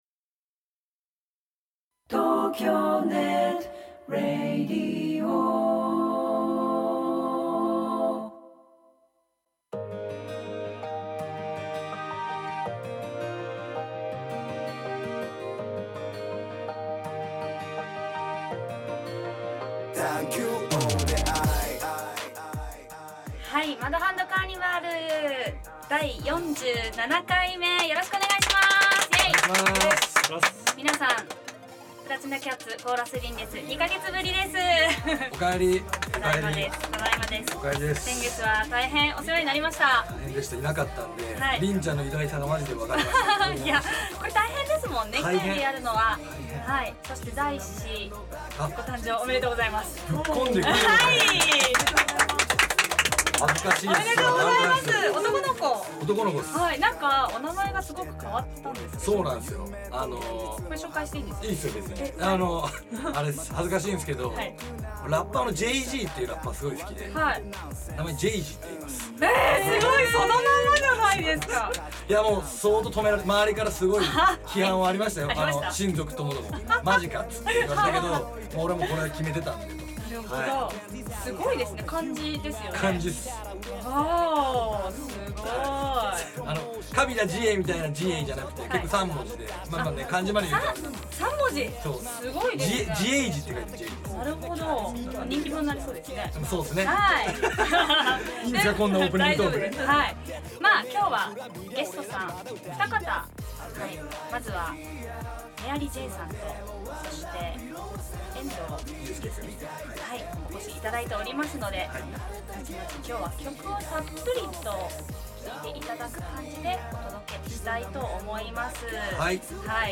とても和気藹々と進行し、時々僕の 低い声と高い笑い声が聞こえますよ。